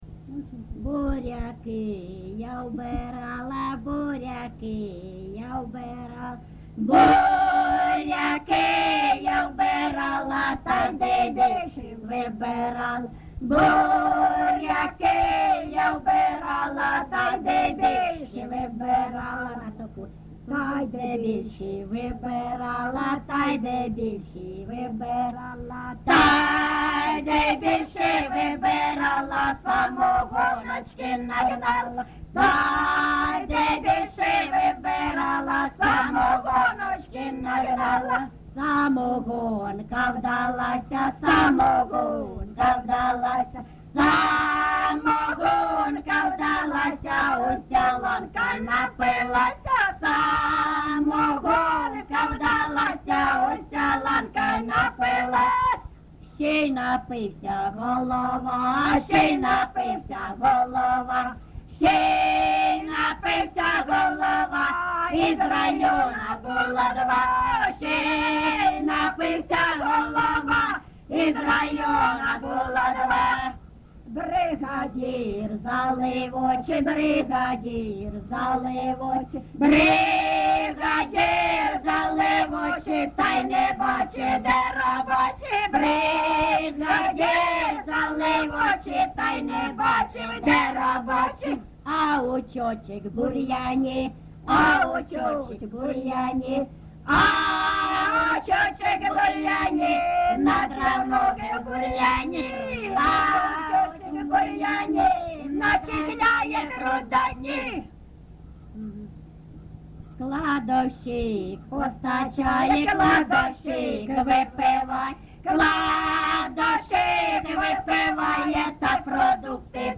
ЖанрКолгоспні, Жартівливі
Місце записус. Свято-Покровське, Бахмутський район, Донецька обл., Україна, Слобожанщина